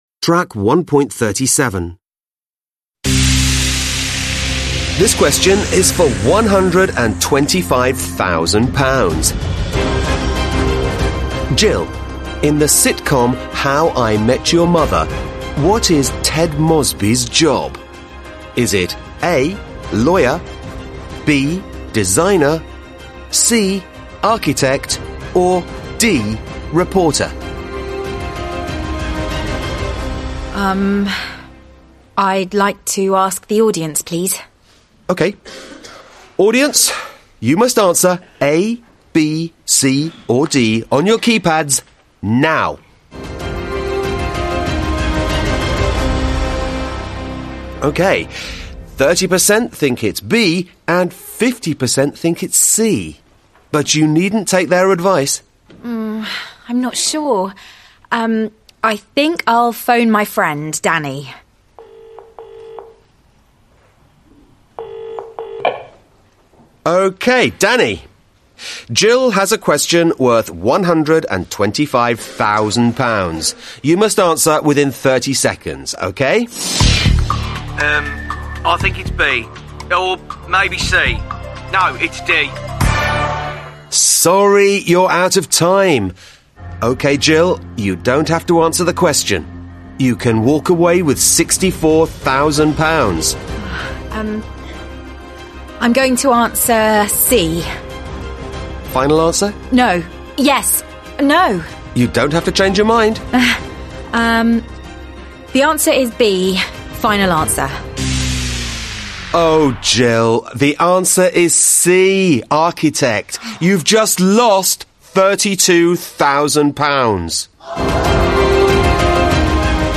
2 (trang 38 Tiếng Anh 10 Friends Global) Read and listen to the dialogue and circle the correct verbs.